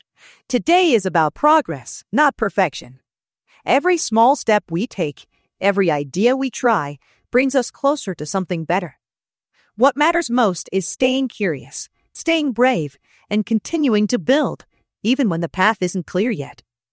Mureka'nın yapay zeka modeli ile Metni Profesyonel Seslere Dönüştürün